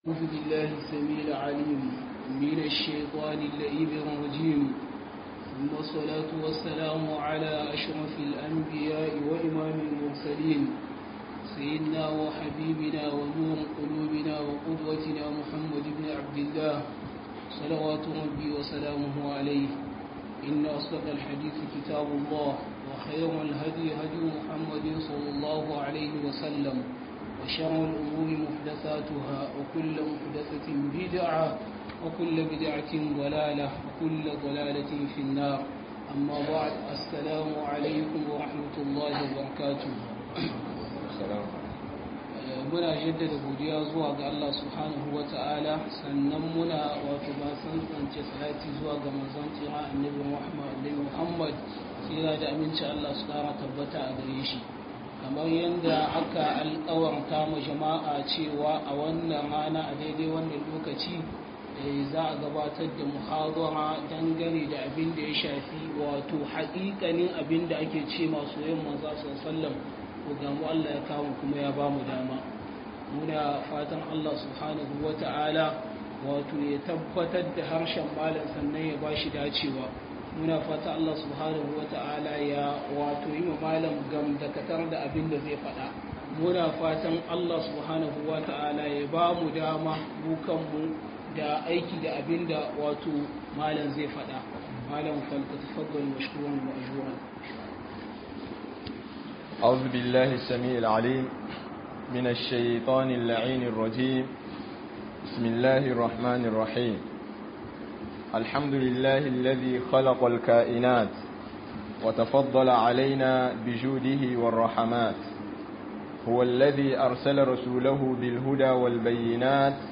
Hakikanin Soyayyar Annabi (S.A.W) - Muhadara